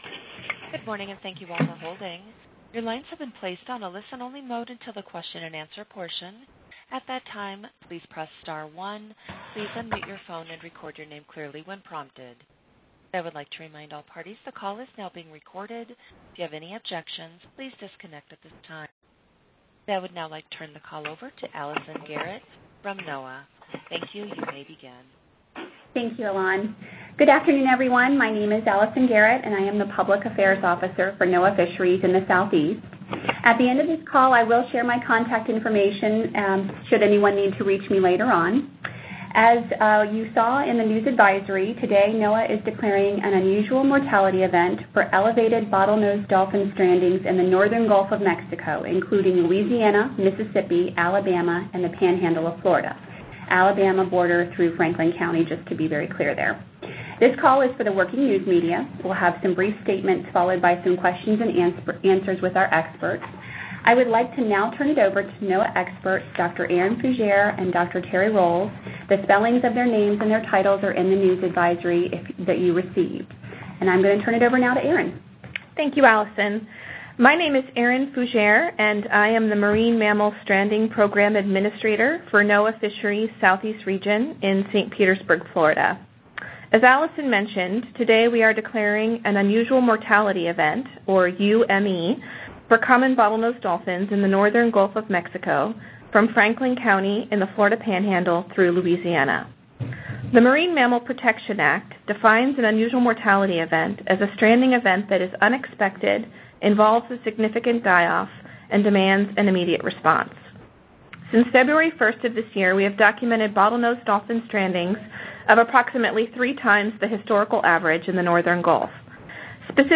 NOAA is hosting a media teleconference today to discuss the unusual mortality event (UME) declared for the elevated bottlenose dolphin strandings in the Northern Gulf of Mexico including Louisiana, Mississippi, Alabama, and the panhandle of Florida east through Franklin County.